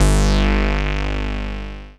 BASS 01.WAV